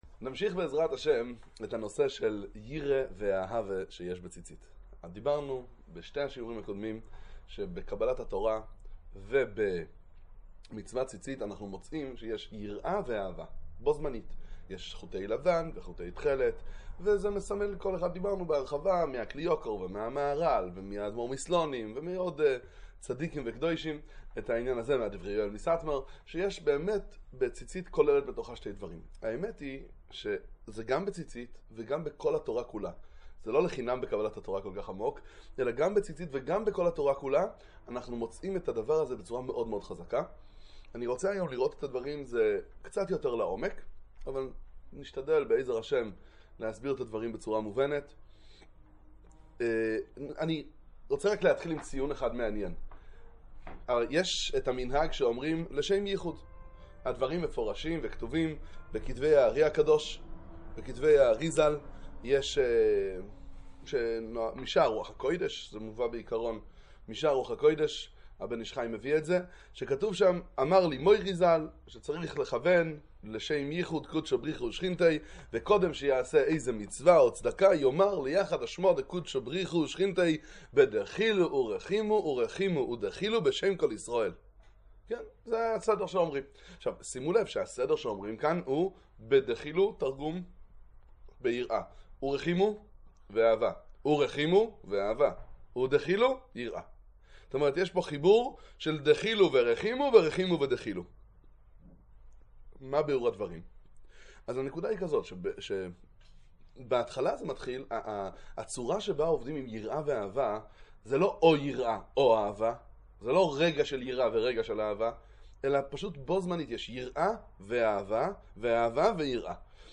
שיעור שלישי בענין אהבה ויראה. עבודת היראה בתורת החסידות.